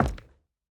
Plastic_004.wav